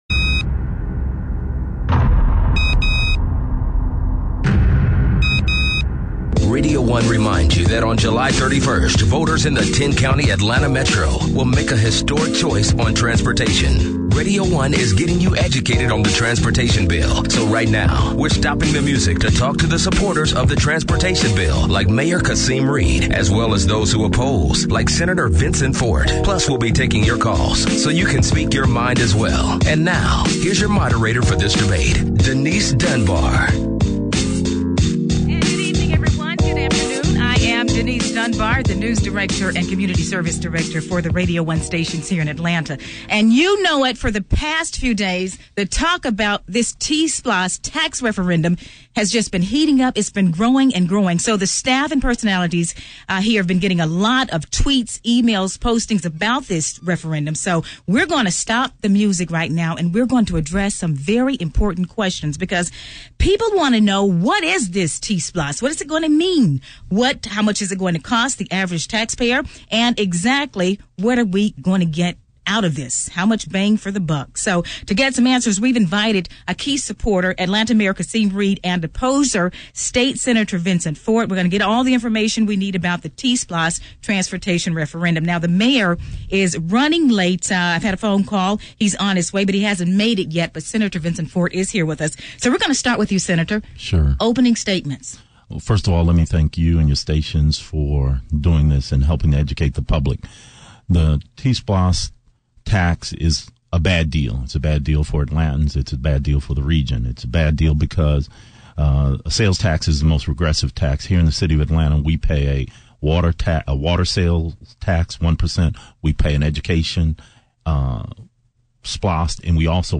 Mayor Kasim Reed & Sen. Vincent Fort Have Heated Debate On T-Splost Transportation Referendum [EXCLUSIVE]
Atlanta Mayor Kasim Reed and state Senator Vincent Fort stopped by our stations today and engaged in a heated debate over the T-Splost transportation referendum.